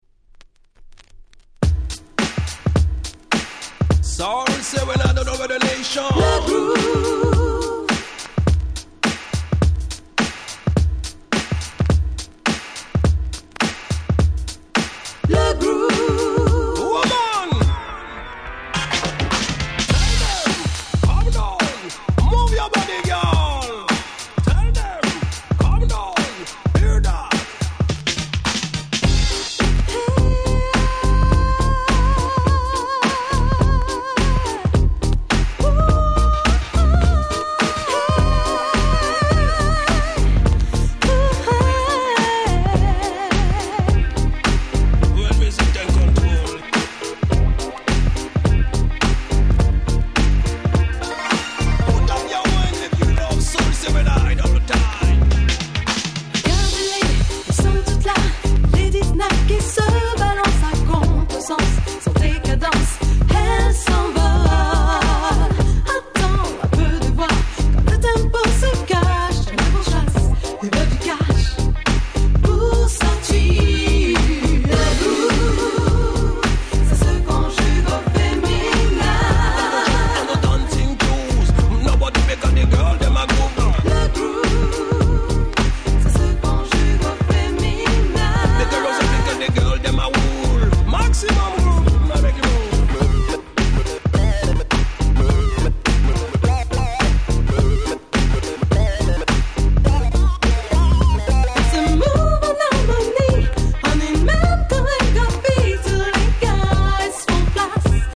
お馴染みのADM調BeatにキャッチーでSoulfulなVocalは最高の一言！！